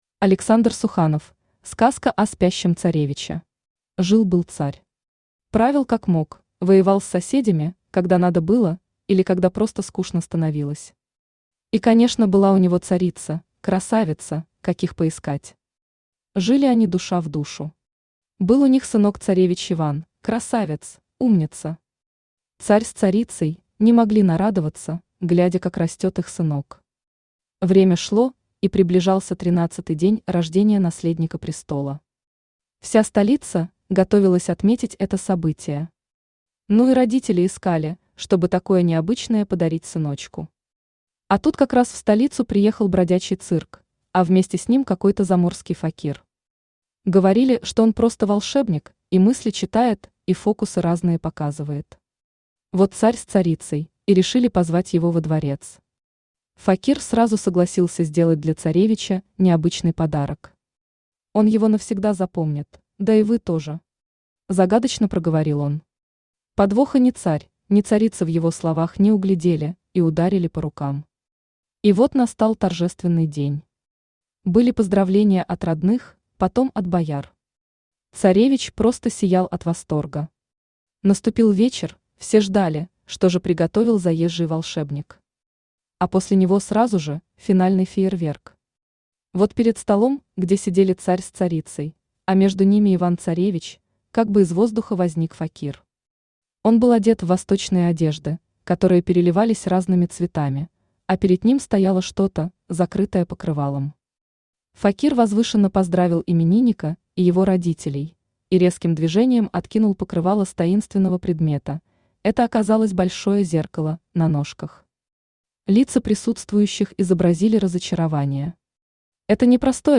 Аудиокнига Сказка о спящем царевиче | Библиотека аудиокниг
Aудиокнига Сказка о спящем царевиче Автор Александр Суханов Читает аудиокнигу Авточтец ЛитРес.